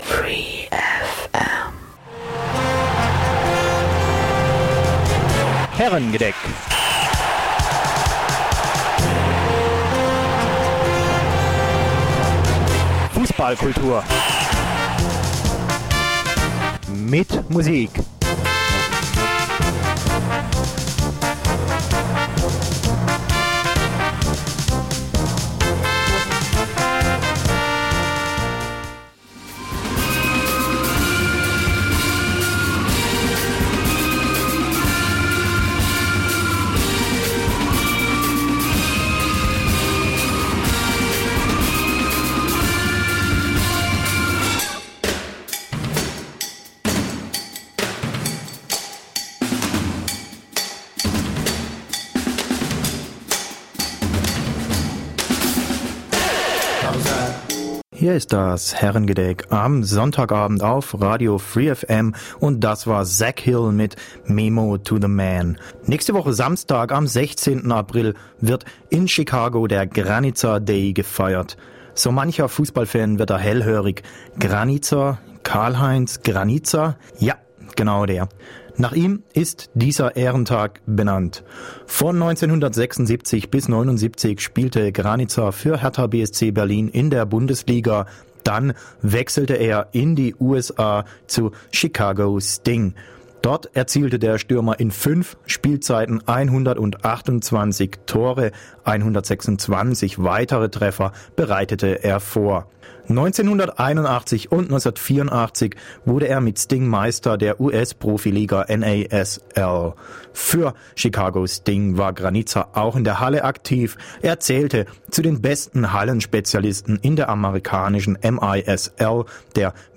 Herrengedeck 17.08.2011 | 16:40 #22 Karl-Heinz Granitza im Interview 1981 und 1984 gewann er mit den Chicago Sting die US-Meisterschaft, 1982 wurde er zu Chicagos Sportler des Jahres gewählt und 2003 in die National Soccer Hall of Fame aufgenommen.